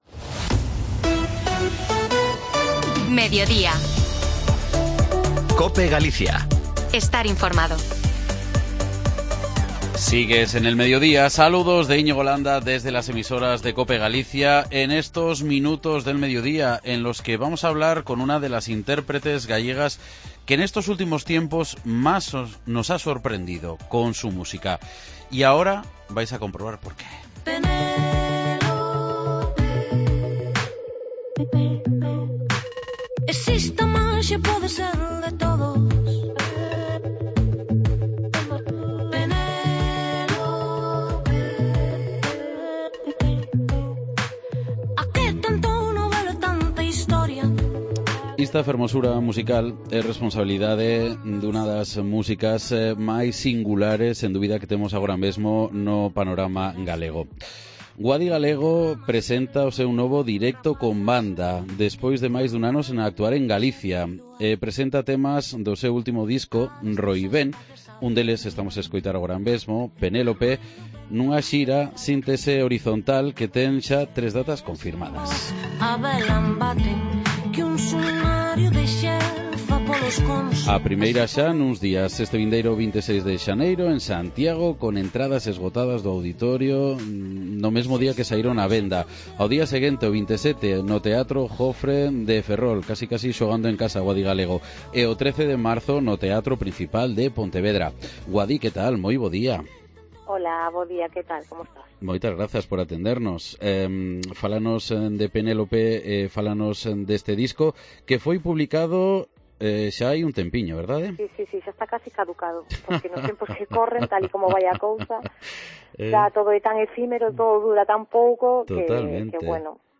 AUDIO: Falamos coa música galega Guadi Galego que presenta na xira Síntese Horizontal os temas do seu último disco Roibén e mais...